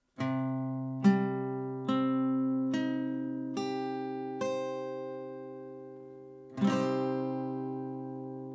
Der c-Moll-Akkord besteht aus den drei Tönen: C, Es und G, die auch als Dreiklang bezeichnet werden.
c-Moll (Barré, E-Saite)
C-Moll-Akkord, Barre E
C-Moll-Barre-E.mp3